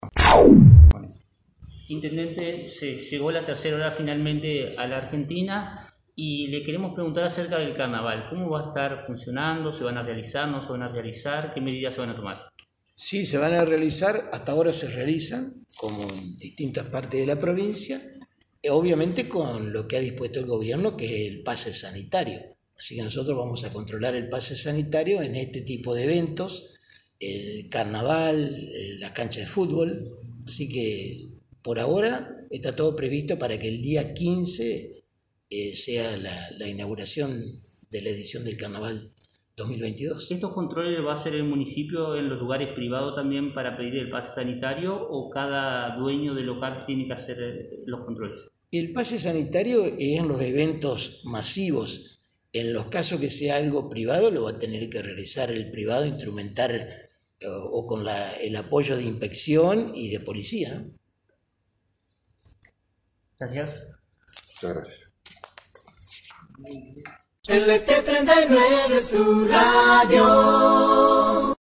En la mañana de este miércoles, en el marco de una conferencia de prensa en la que se dio a conocer el decreto que establece la emergencia hídrica en nuestra ciudad, el intendente Domingo Maiocco confirmó que no se suspenderá, por el momento, la edición 2022 “Fidel Aquino” de los carnavales victorienses.
Intendente Domingo Maiocco